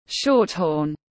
Shorthorn /ˈʃɔːt.hɔːn/